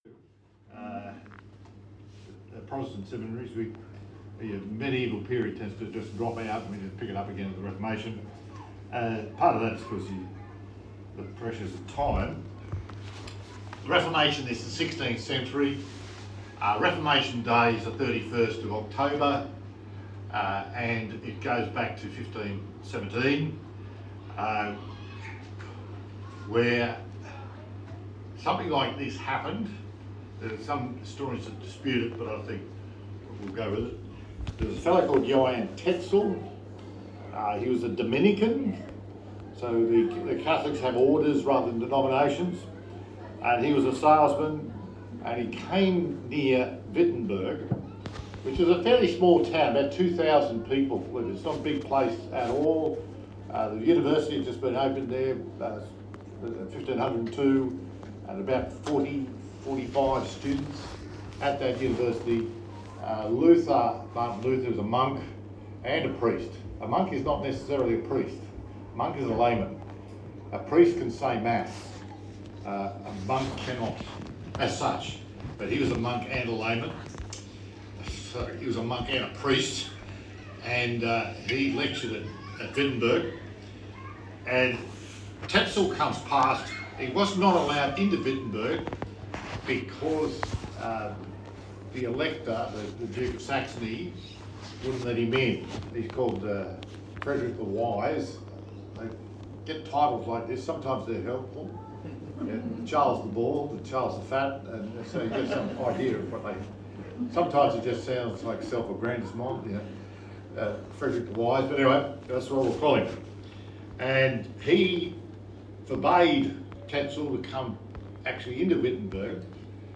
Service Type: Church Camp Talk